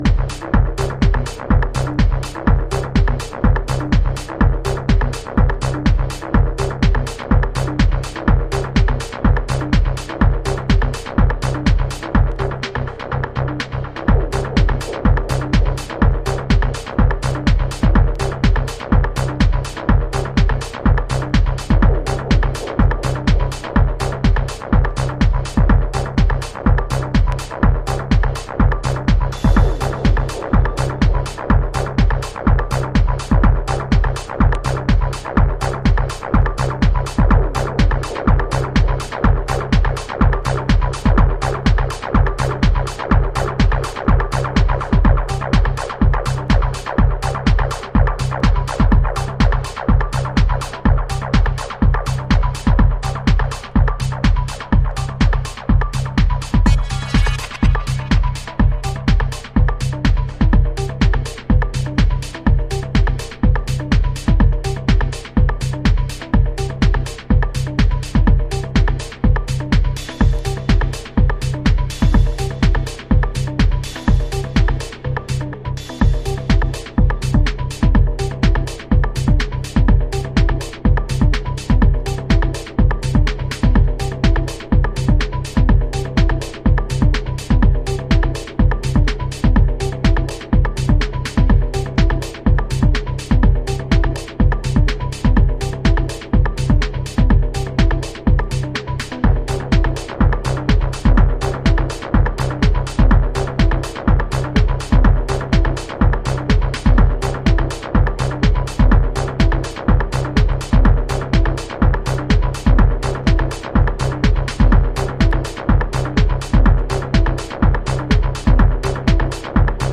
House / Techno
重心低めのドープなグルーヴにピッチを下げたボコーダーボイス控えめアシッドがバッドトリップを誘発！